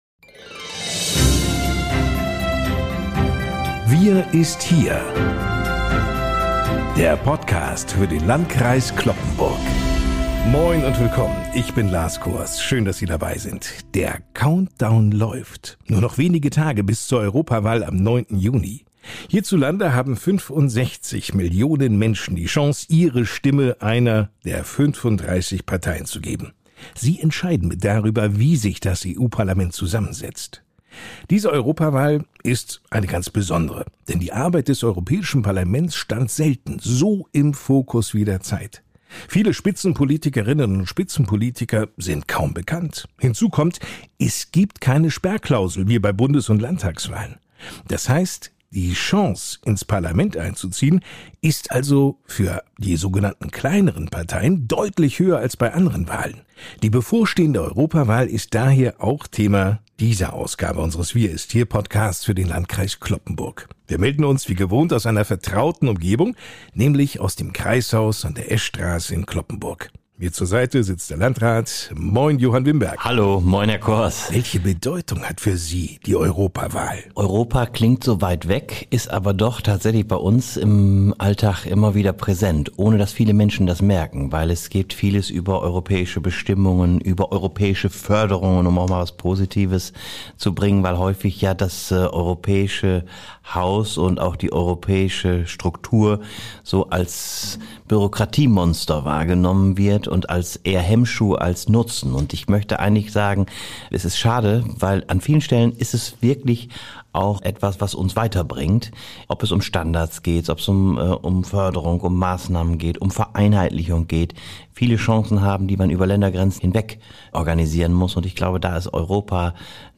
Der Podcast für den Landkreis Cloppenburg ist ein fesselndes und informatives Format, das alle 14 Tage freitags veröffentlicht wird.